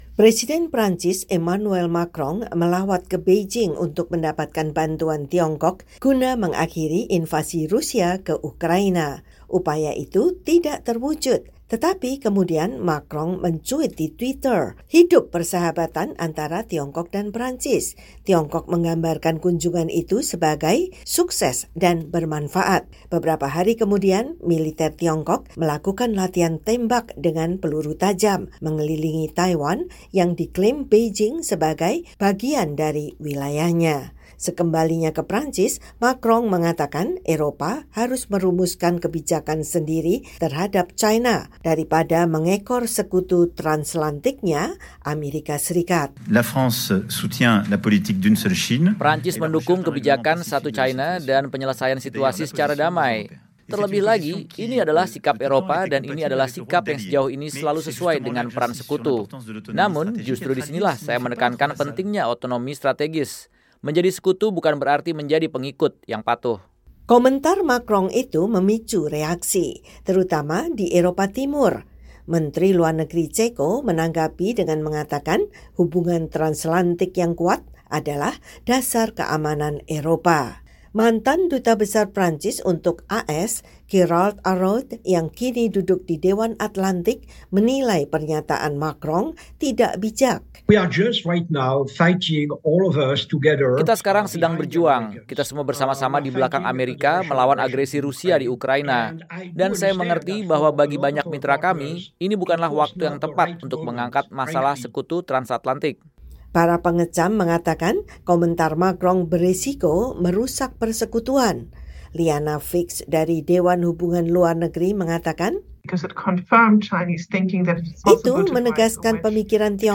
Laporan